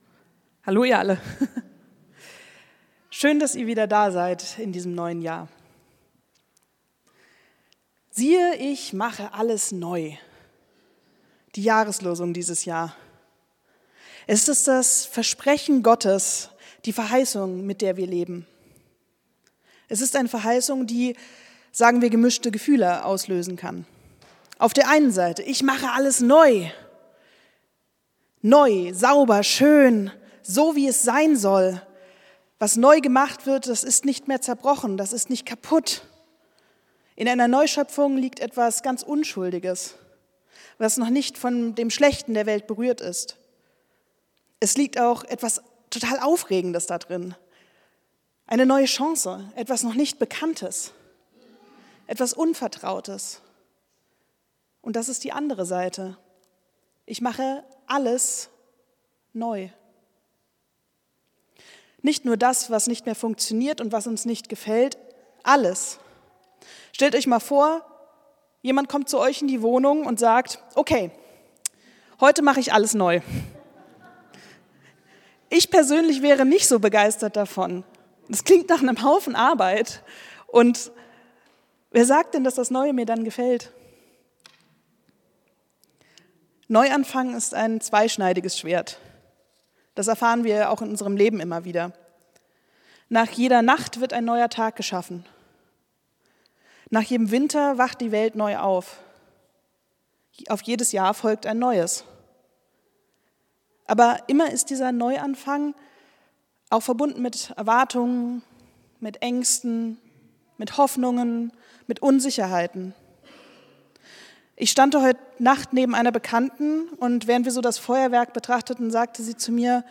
Predigt vom 01.01.2026